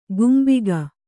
♪ gumbiga